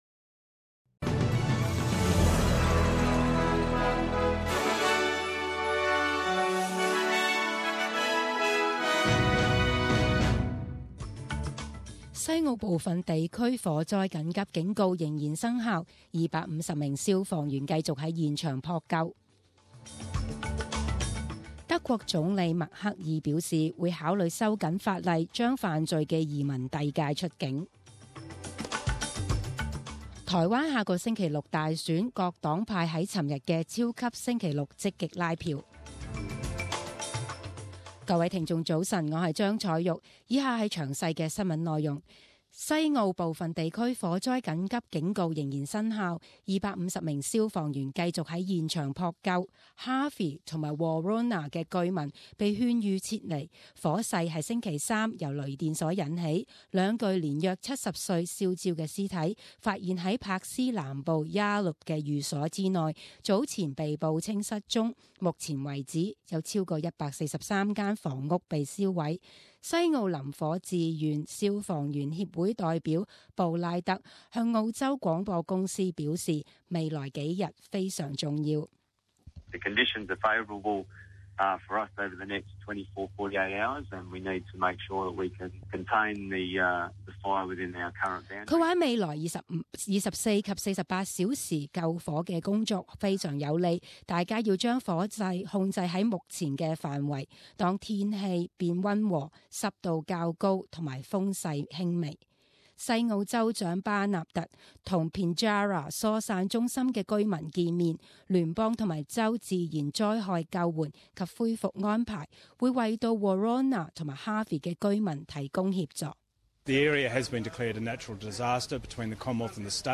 十点钟新闻报导 （一月十日）